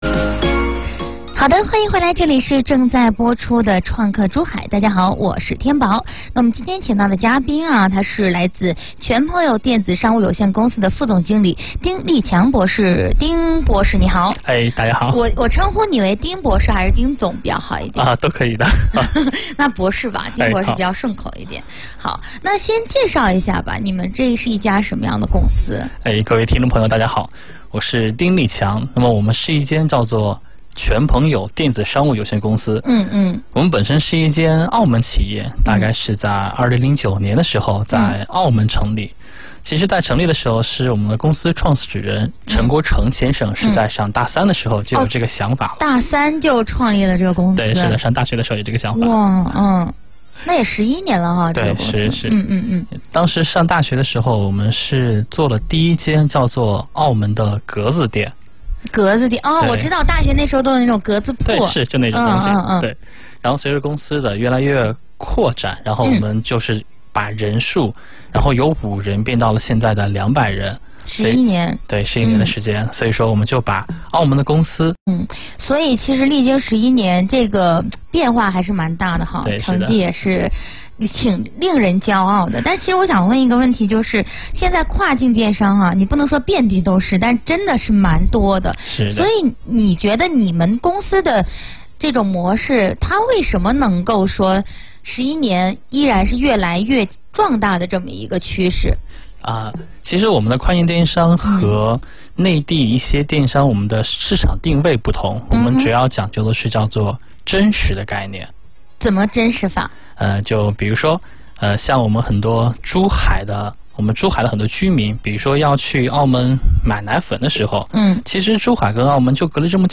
創客珠海——珠海電台交通875專訪